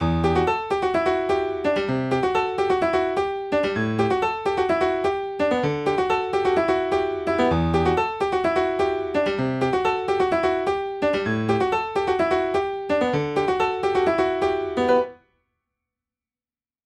弄くり回して編集した8小節のMIDIフレーズはこうなる
全体的な音を左右にずらしたり、一部の音を抜いたり、トランスでサイドチェインを使用して鳴るシンセの音を考慮しながら、ピアノロールでMIDIデータを編集していきます。
最初のギターのアルペジオのMIDIデータと聴き比べると雰囲気は似ていますが、コード進行などは違うものになります。